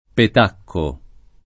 [ pet # kko ]